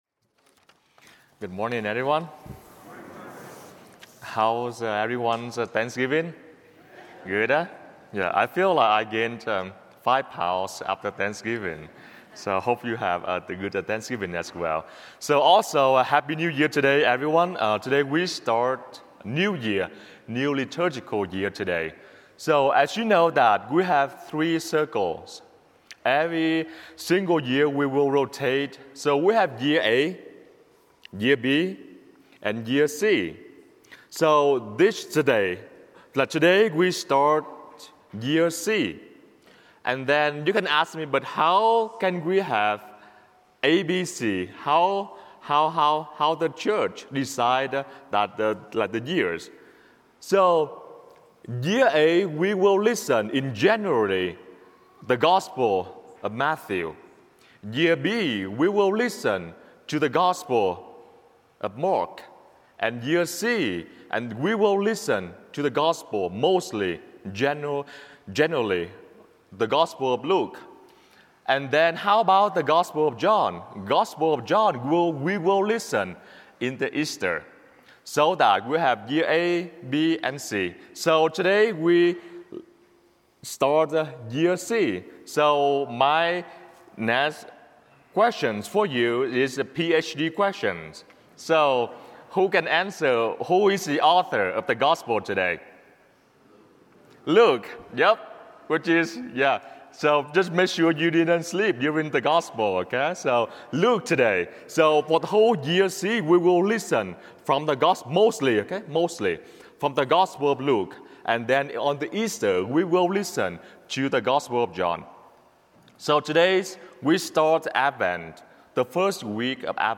Homilies